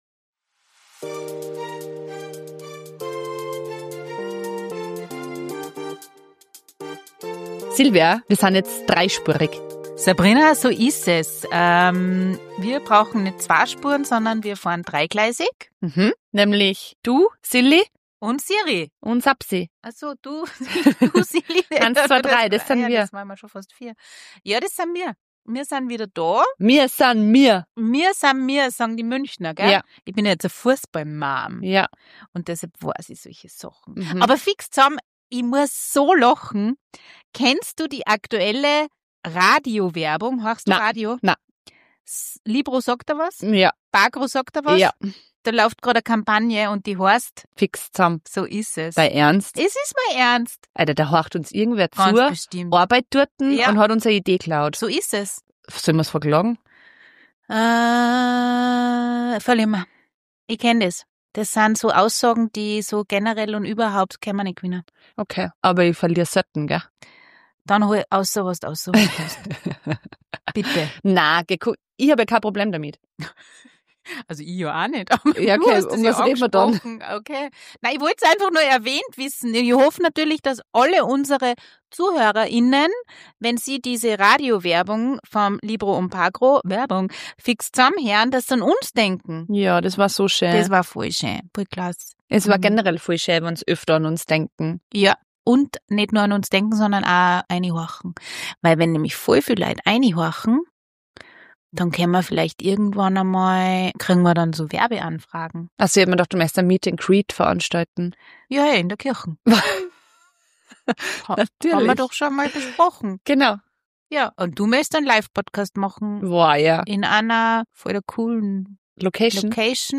Zwischen frechen Sprüchen und ehrlichen Einblicken plaudern wir über Planerinnen-Drama, Männer-Chaos und die Kunst, No-Gos klar zu kommunizieren. Insider-Gags, persönliche Anekdoten und a bissal Gezicke inklusive! Ob Geschenketisch, Gruppenfoto-Hoppalas oder „fancy“ Missverständnisse – hier kommt alles auf den Tisch!